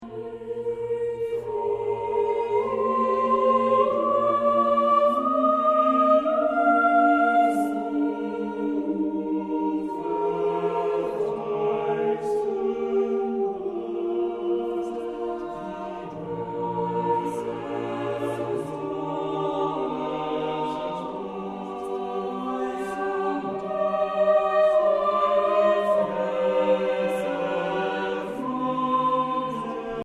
Eine Aufnahme überwiegend romantischer Chorliteratur
klangschönen und nuancenreichen Gesang